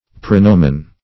Prenomen \Pre*no"men\, n.
prenomen.mp3